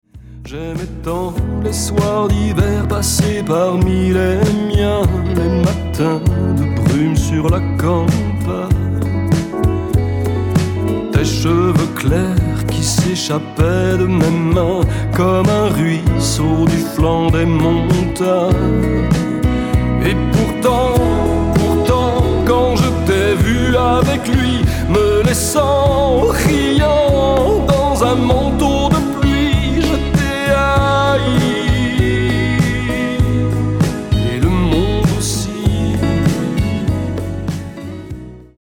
Basse
Batterie et percussions
Guitares
Claviers